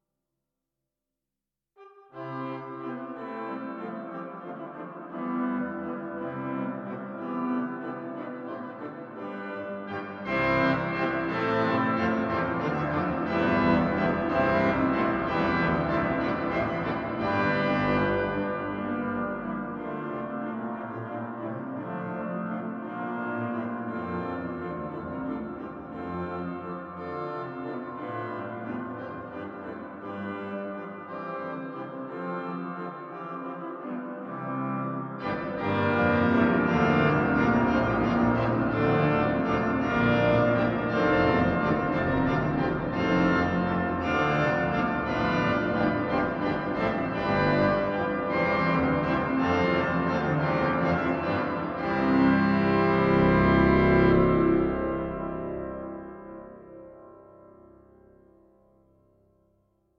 Met de keuze uit 12 verschillende convolutie galmen, kun je je ruimtelijke voorkeur geheel naar eigen wens instellen.
Het symfonische orgel is voorzien van een mooie mix van stemmen uit het Cavaille Coll orgel van de Église Notre-Dame d’Auteuil in Parijs en het Adema orgel in de Basiliek van de Heilige Kruisverheffing in Raalte.
Etude-Symfonisch-Boelman.mp3